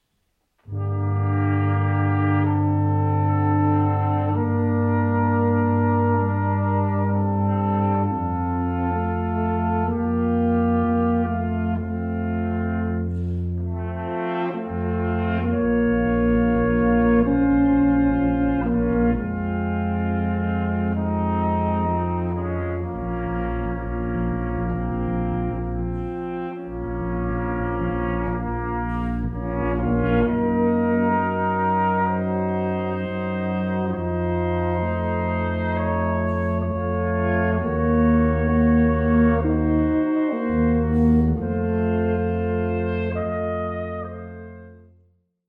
Sakrale Musik für Blechbläserquartett